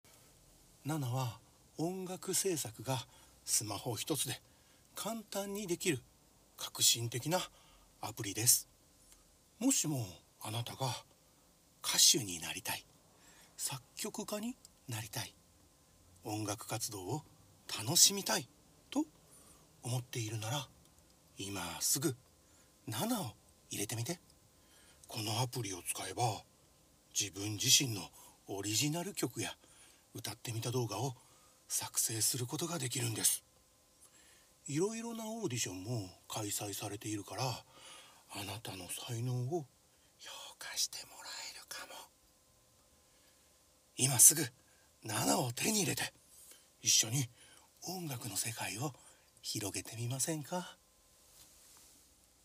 nanaナレーターオーディション